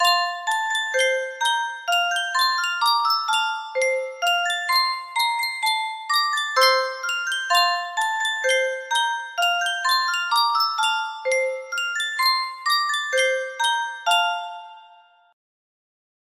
Sankyo Music Box - Waltzing Matilda TF music box melody
Full range 60